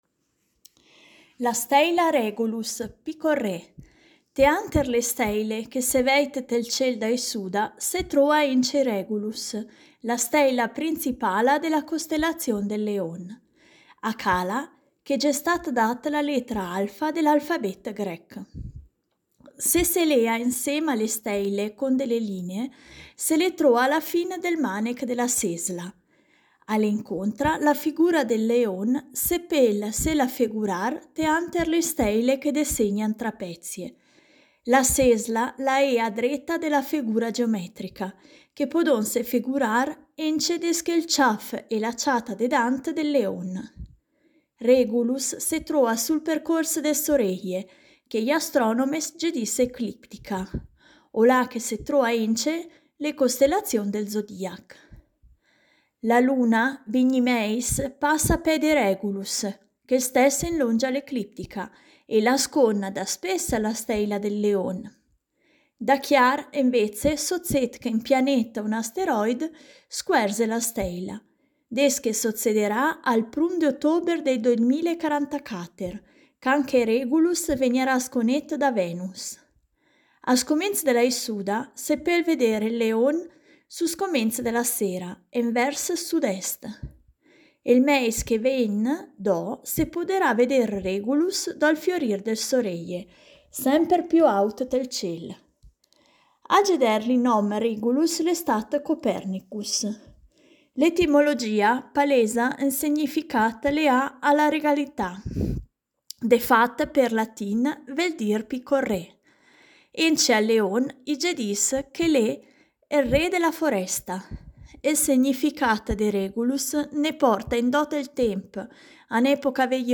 Il progetto “Idiomi celesti” promuove l’osservazione ad occhio nudo del cielo stellato con testi scritti e letti ad alta voce in tutte le lingue, dialetti compresi, come ad esempio la lingua ladina e il dialetto lumezzanese.